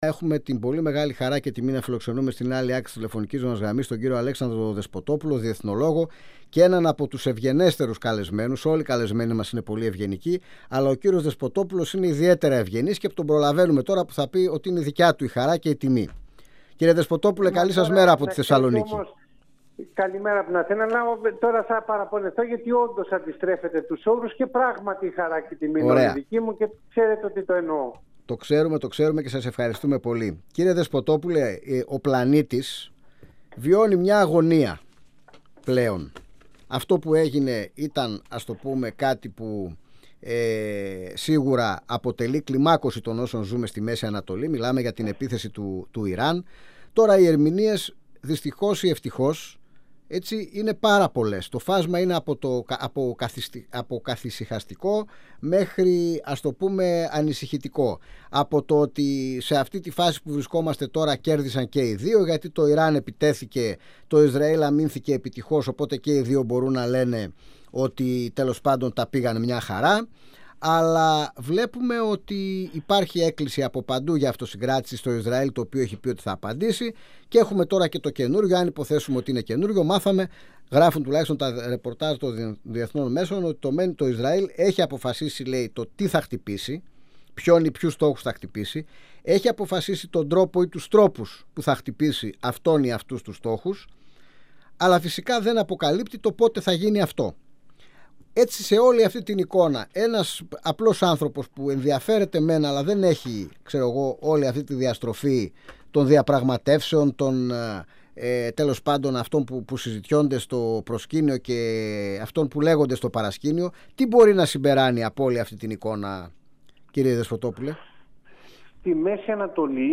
μιλώντας στην εκπομπή «Αίθουσα Σύνταξης» του 102FM της ΕΡΤ3.
Αιθουσα Συνταξης Συνεντεύξεις